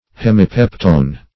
Hemipeptone \Hem`i*pep"tone\, n. [Hemi- + peptone.]